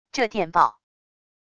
这电报wav音频